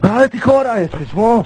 Worms speechbanks
bummer.wav